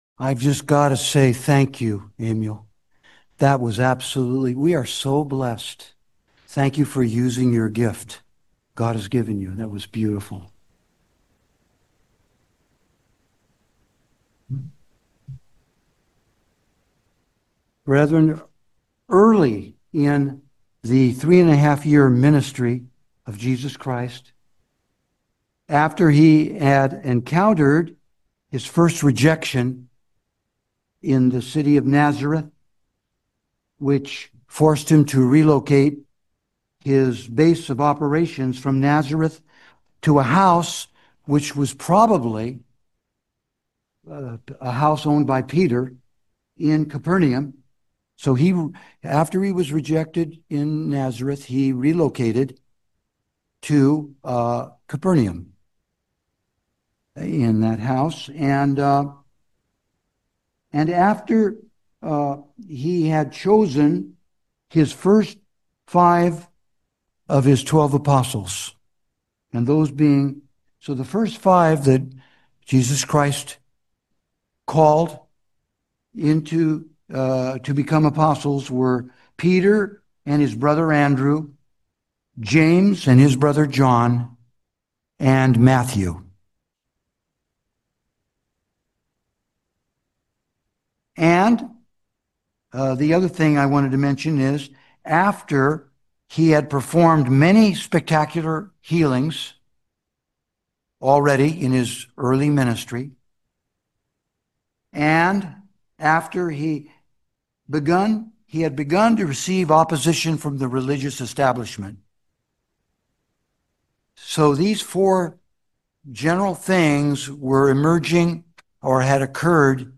This sermon explains the true intent of these parables, and contrasts that with the main false interpretation that is commonly taught by traditional Christianity.